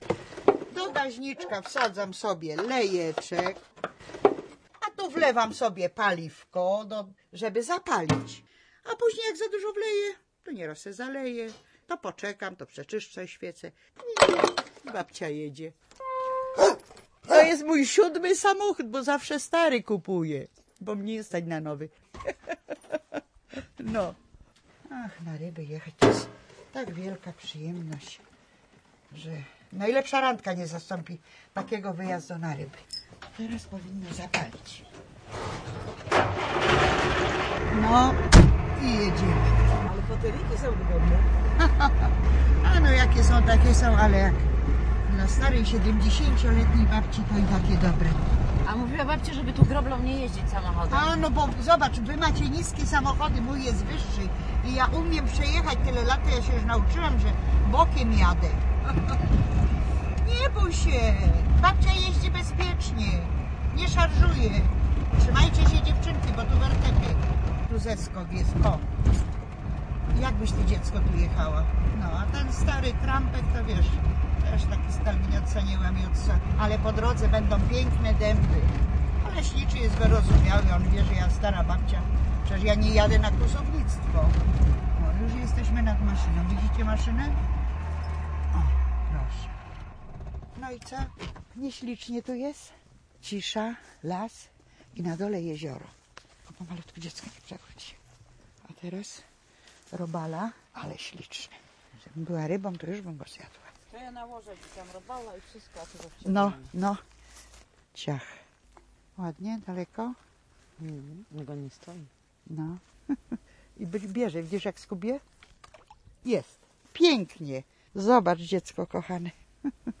W sobotę na naszej antenie reportaż sprzed lat